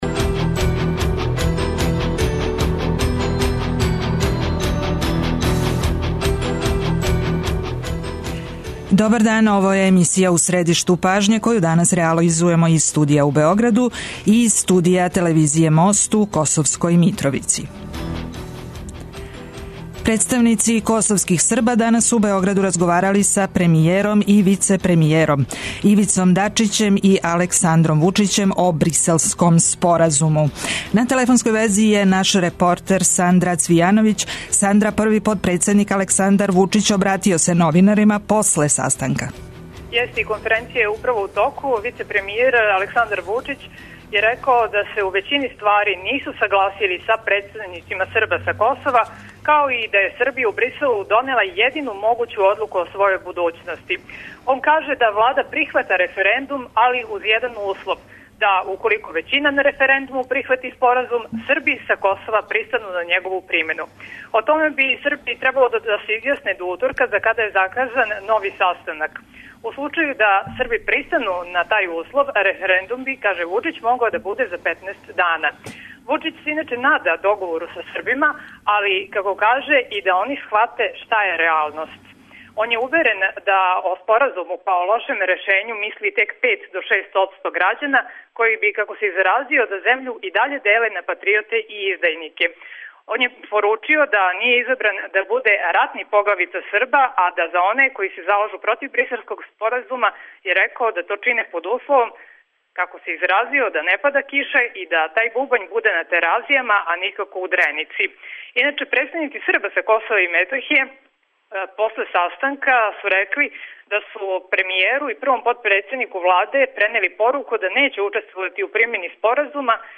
Екипа емисије У средишту пажње је у Косовској Митровици где ће разговарати са представницима Срба, а у студију Радио Београда 1 биће Милован Дрецун, председник Одбора Скупштине Србије за Косово и Метохију.